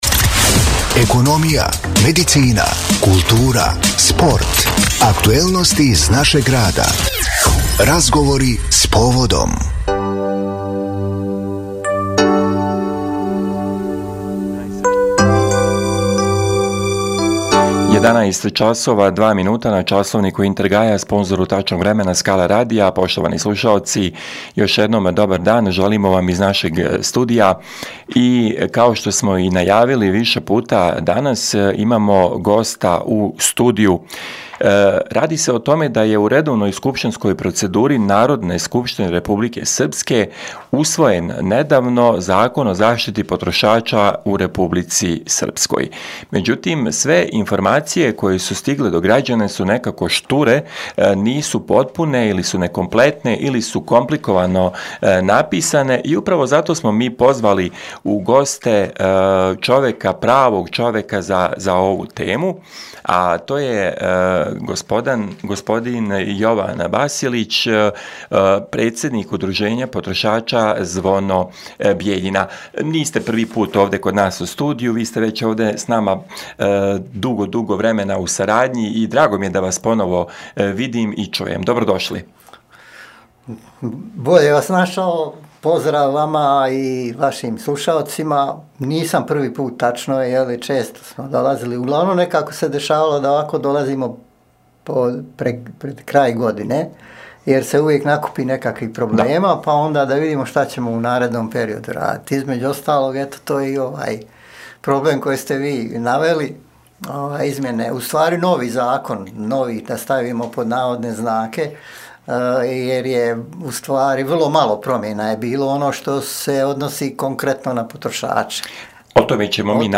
GOST U STUDIJU